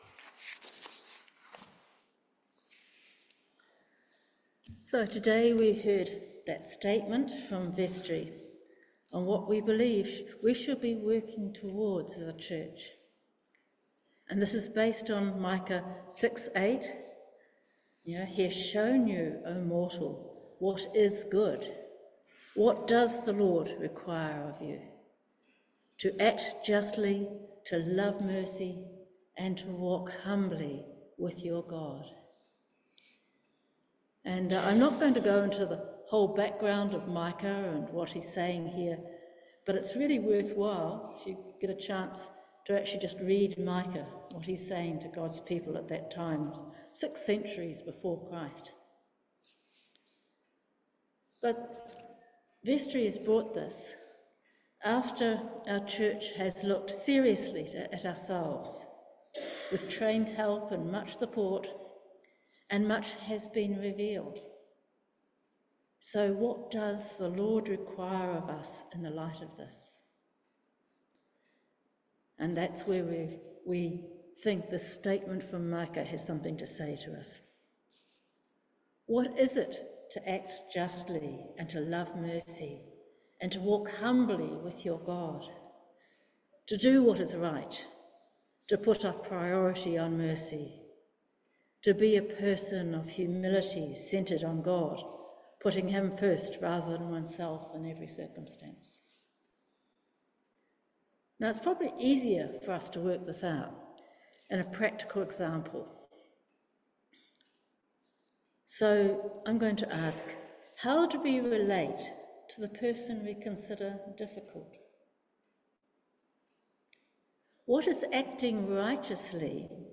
Service Type: Holy Communion